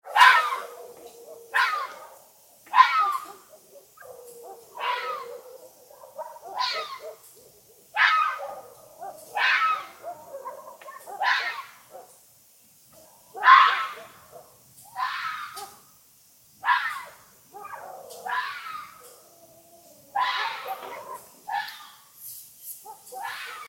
دانلود آهنگ روباه 1 از افکت صوتی انسان و موجودات زنده
دانلود صدای روباه 1 از ساعد نیوز با لینک مستقیم و کیفیت بالا
جلوه های صوتی